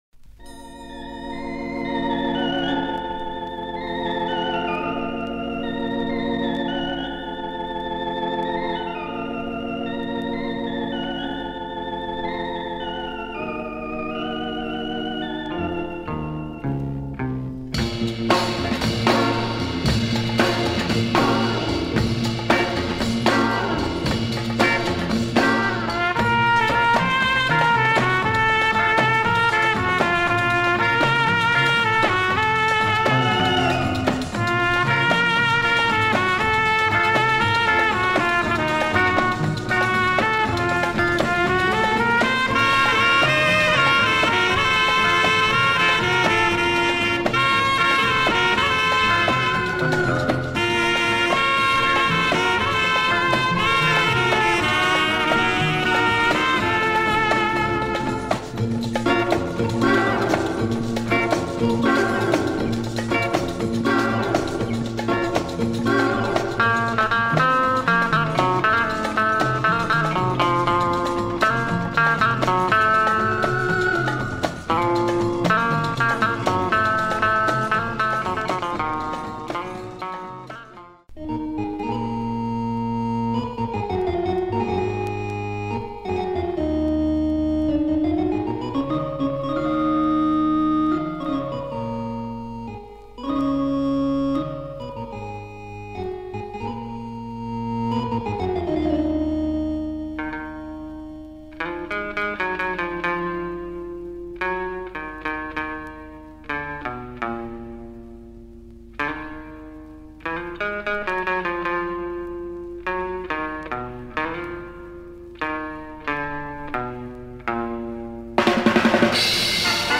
LP